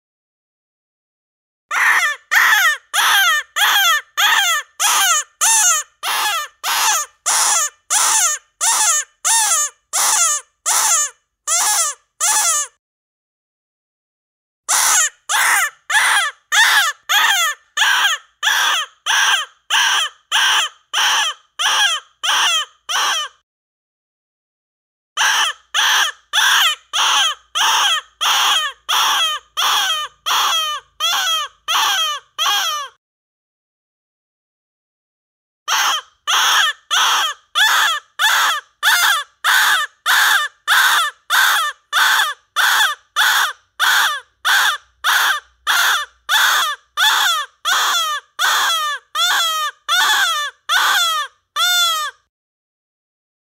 Feldhase Geräusche
Feldhase-Geraeusche-Wildtiere-in-Deutschland.mp3